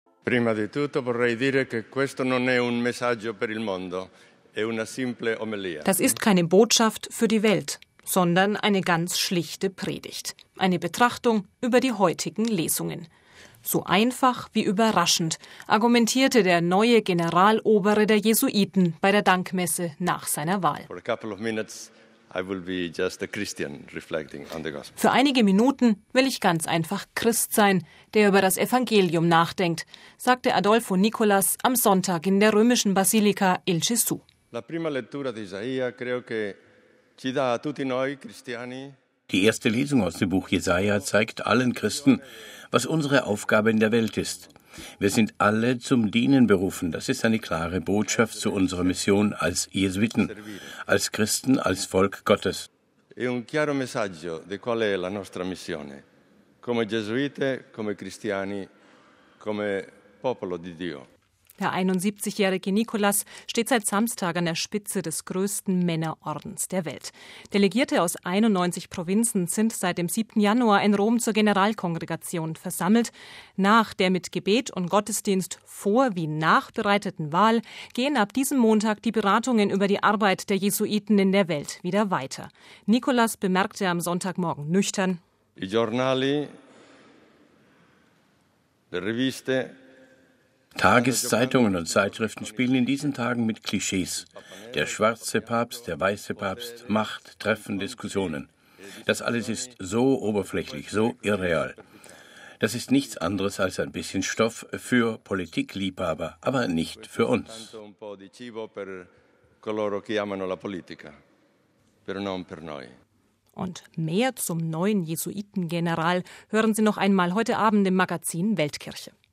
So einfach wie überraschend argumentierte der neue Generalobere der Jesuiten bei der Dankmesse nach seiner Wahl. „Für einige Minuten will ich ganz einfach Christ sein, der über das Evangelium nachdenkt“, sagte Adolfo Nicolas am Sonntag in der römischen Basilika „Il Gesù“.